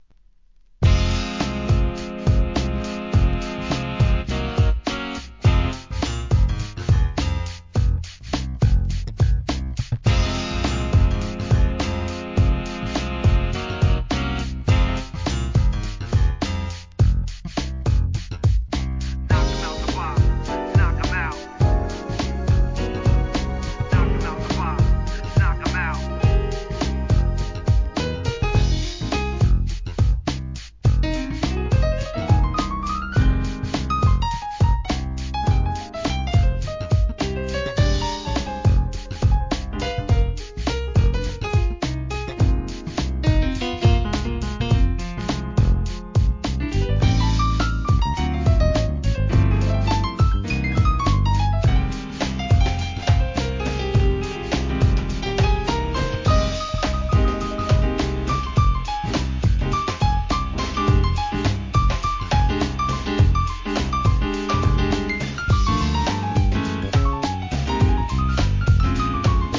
HIP HOP/R&B
HIP HOPのヒット曲の数々をJAZZカヴァーする企画アルバム